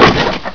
wood1.wav